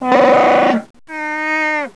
Geräusche
Die Sprache der Wookiees, sie besteht aus Brumm-, Grunz- und Brülllauten.